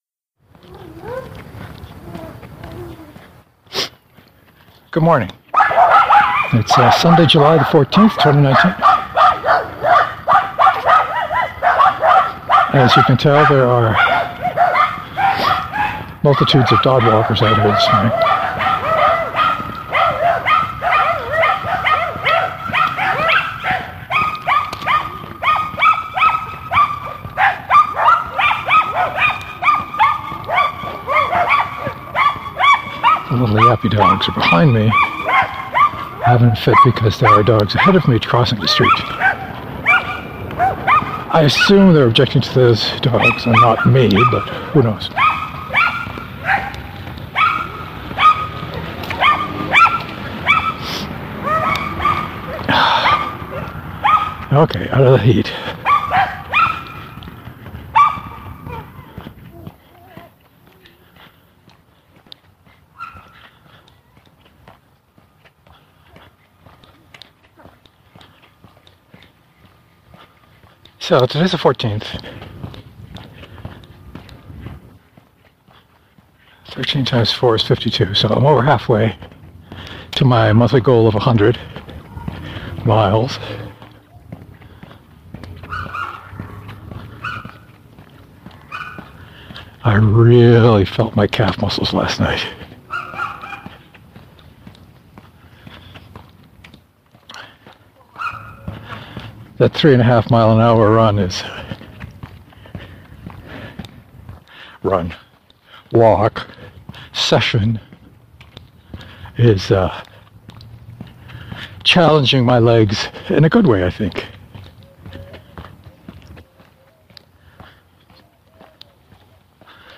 With a lot of dogs barking.